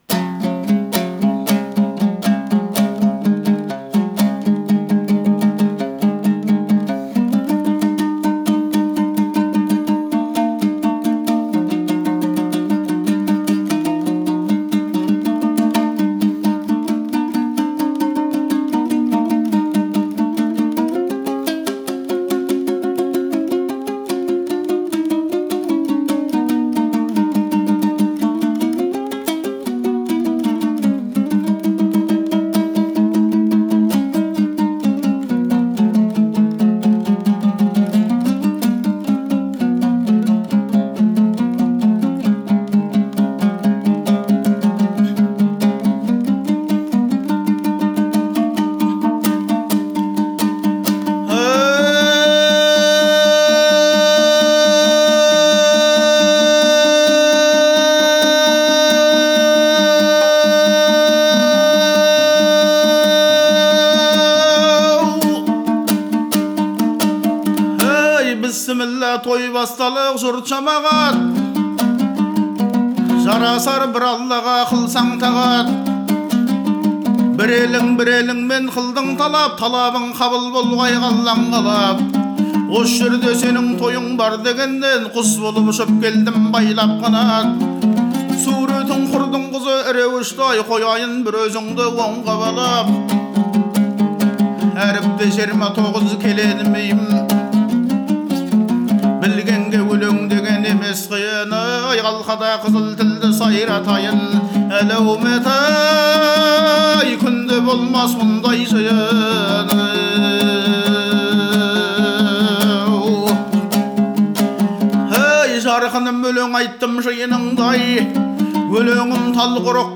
Басты бет Жыр-терме Тойбастар Нота Тойбастар АБЫЛДЫҢ ТОЙБАСТАРЫ Қыз ұзату тойында сөз бастаған ақын қыздың көркі мен адамгершілігін суреттеп, кісілік парасатын бірінші орынға қояды.